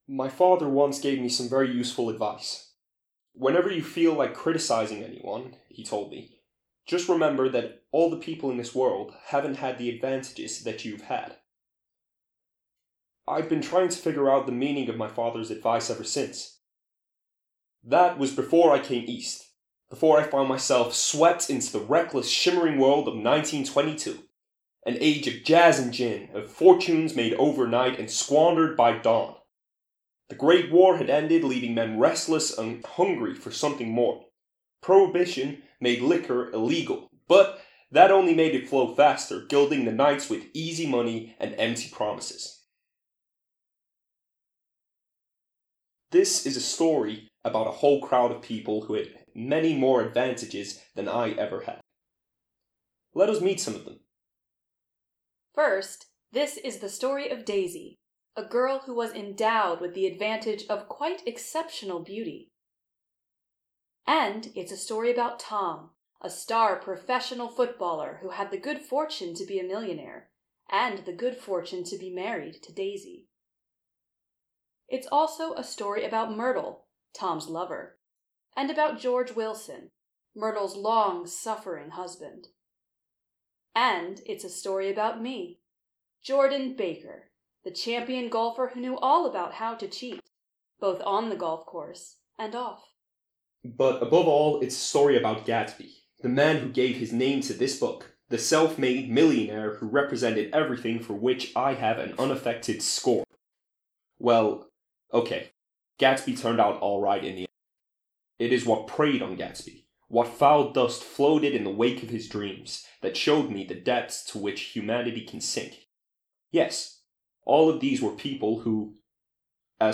Audio Excerpts from 'The Great Gatsby'